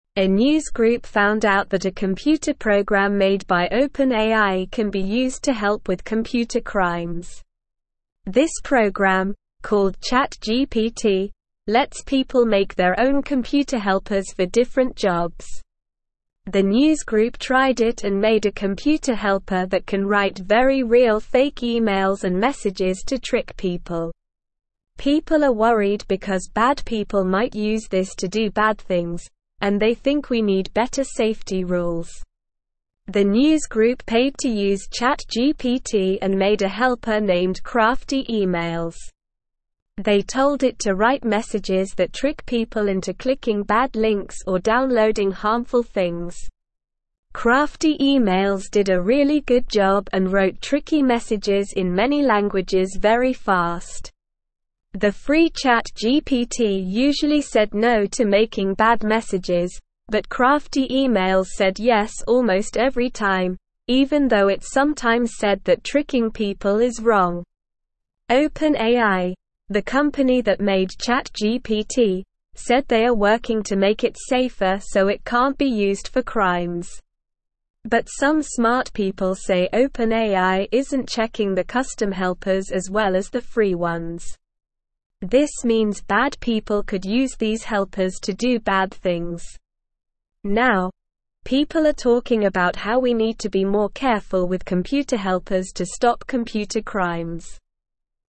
Slow
English-Newsroom-Lower-Intermediate-SLOW-Reading-Bad-Robot-Tricks-People-Company-Working-to-Stop-It.mp3